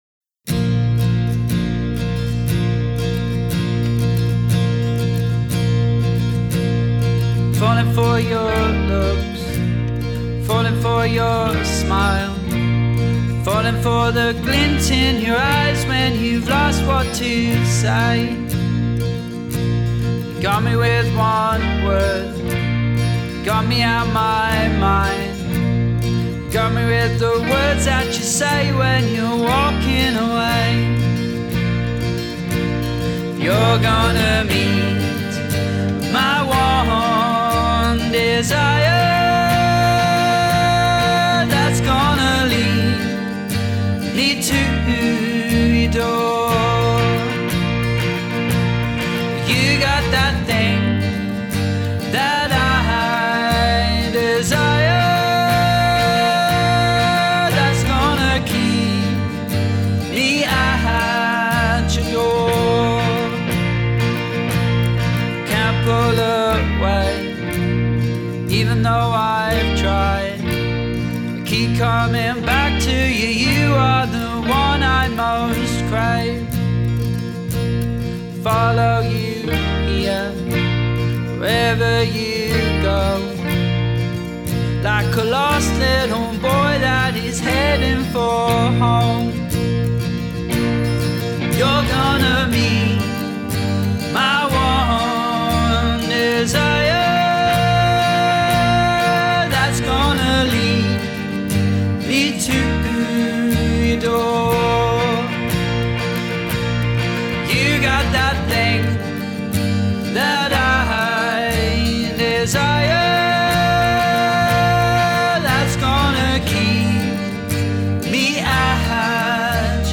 Recorded at home